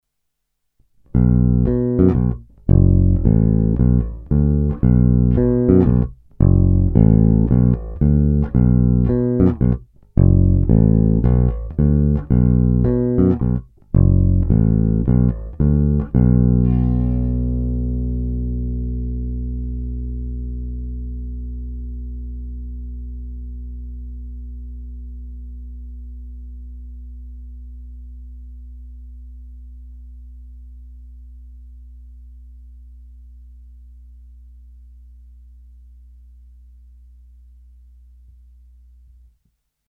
Bručivá, agresívnější, skvěle použitelná i na slapovou techniku.
Není-li uvedeno jinak, všechny nahrávky byly provedeny rovnou do zvukové karty a dále kromě normalizace ponechány bez úprav. Tónová clona byla vždy plně otevřená.
Hra mezi snímačem a kobylkou